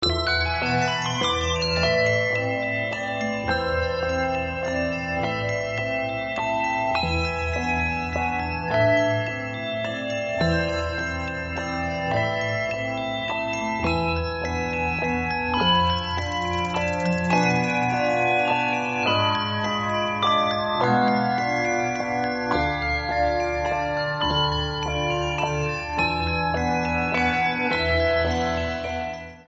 fronted by hand and concert bells.